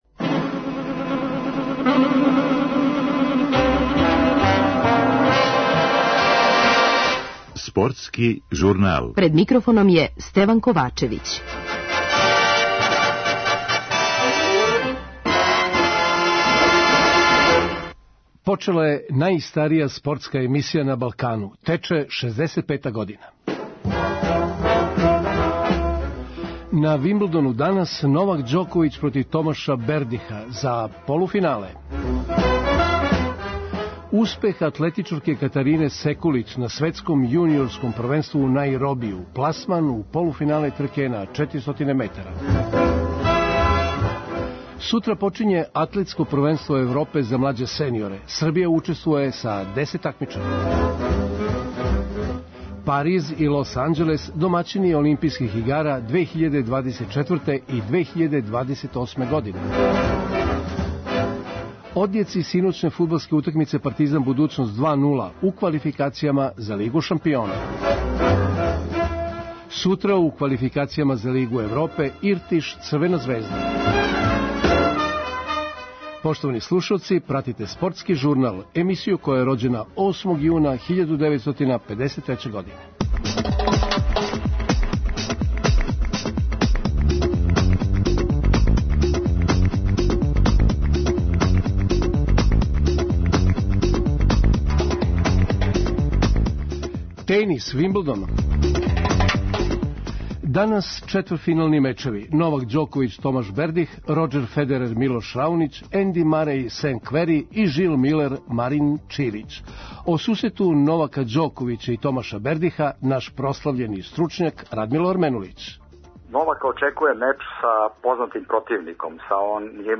преузми : 5.27 MB Спортски журнал Autor: Спортска редакција Радио Београда 1 Слушајте данас оно о чему ћете читати у сутрашњим новинама!